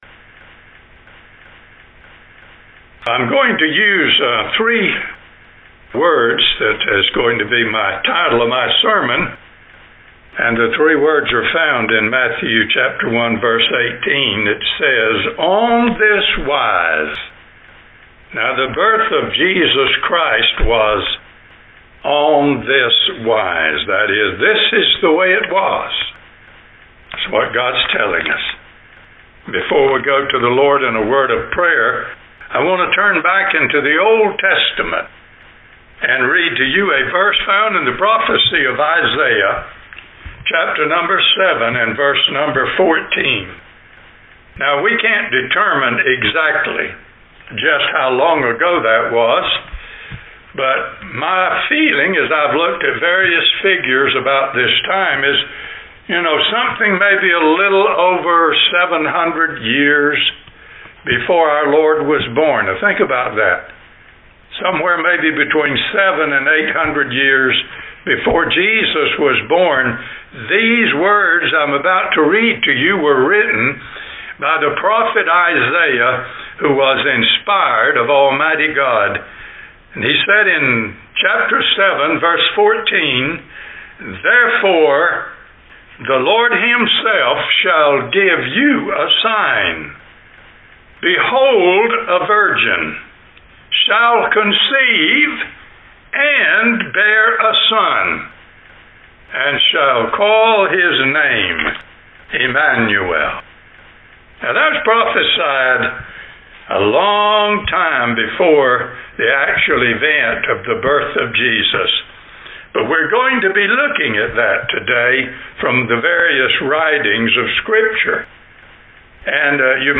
On This Wise Jan 1 In: Sermon by Speaker